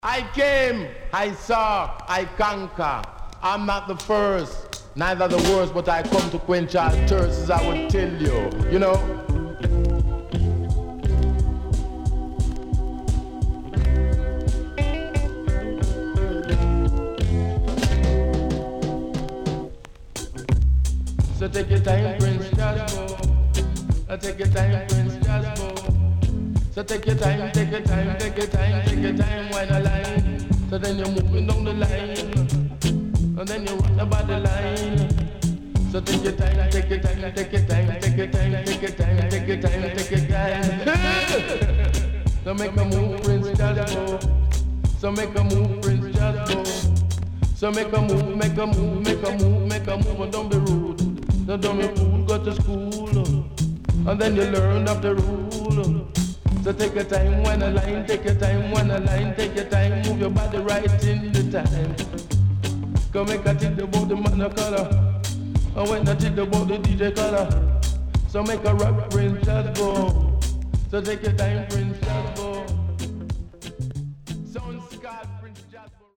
HOME > Back Order [VINTAGE 7inch]  >  70’s DEEJAY
Self Produce.Nice Deejay
SIDE A:少しノイズ入りますが良好です。